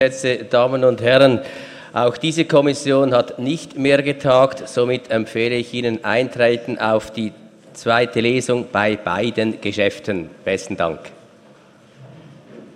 12.6.2017Wortmeldung
Session des Kantonsrates vom 12. und 13. Juni 2017